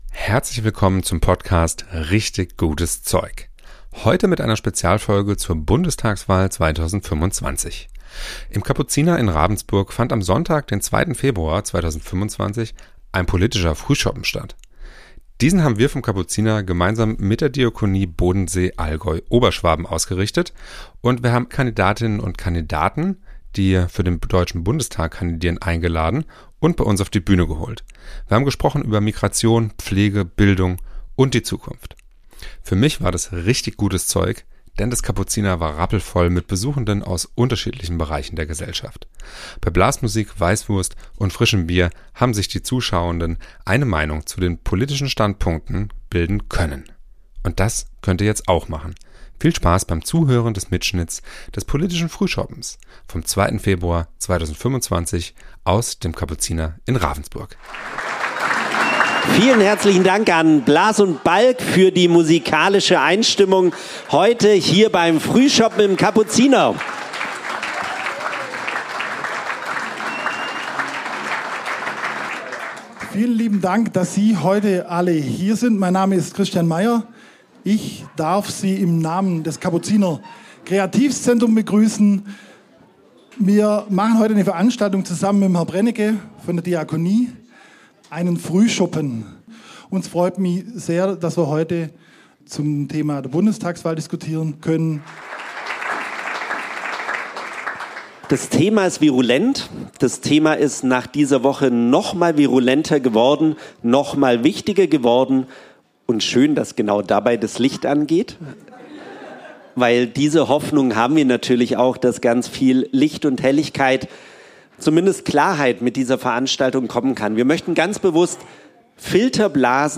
Zur bevorstehenden Bundestagswahl hat das Kapuziner gemeinsam mit der Diakonie Oberschwaben Allgäu Bodensee am 02. Februar 2025 zum politischen Frühschoppen ins Kapuziner nach Ravensburg eingeladen.